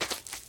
step_grass.2.ogg